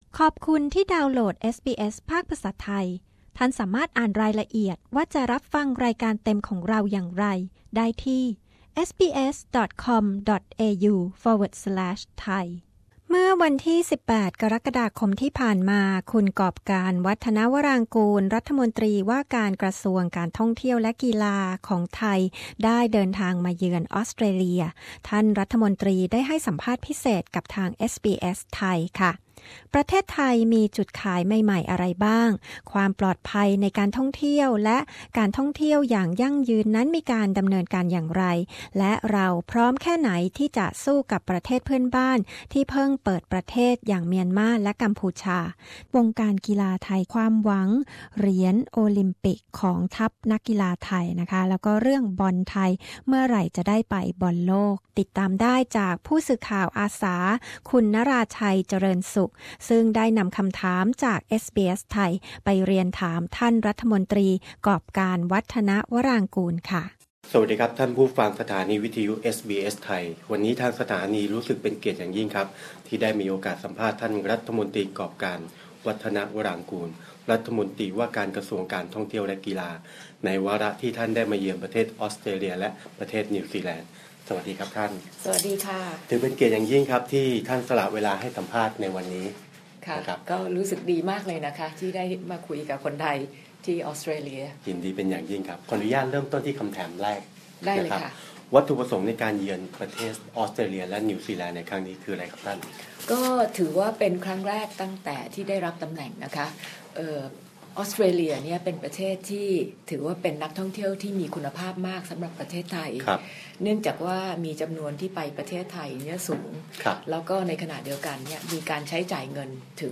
คุยเรื่องจุดขายใหม่ด้านการท่องเที่ยวไทย เราพร้อมสู้กับประเทศเพื่อนบ้านที่เพิ่งเปิดประเทศต้อนรับนักท่องเที่ยวอย่างไร และไทยมีความหวังแค่ไหนกับทัพนักกีฬาไทย ในโอลิมปิกส์ ที่ริโอ คุณกอบกาญจน์ วัฒนวรางกูร รัฐมนตรีว่าการกระทรวงท่องเที่ยวและกีฬาของไทย ให้สัมภาษณ์เรื่องนี้กับ เอสบีเอส ไทย